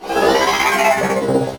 combat / enemy / droid / die3.ogg